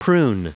Prononciation du mot prune en anglais (fichier audio)
Prononciation du mot : prune